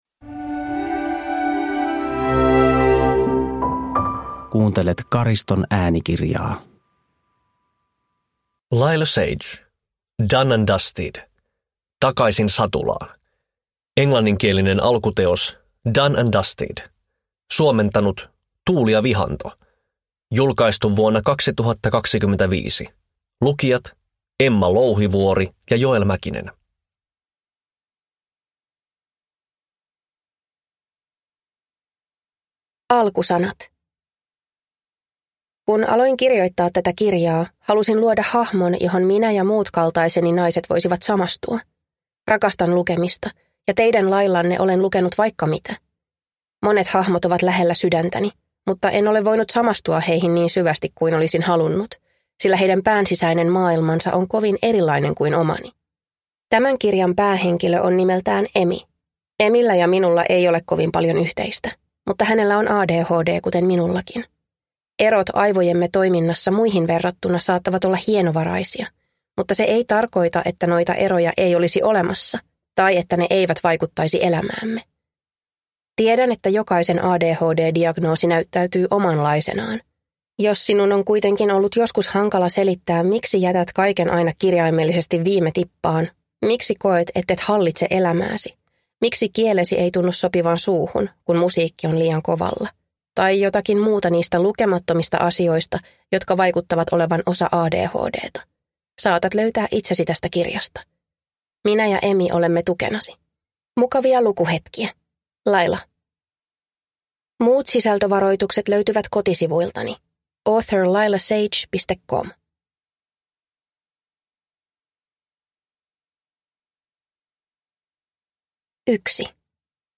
Done and Dusted – Takaisin satulaan – Ljudbok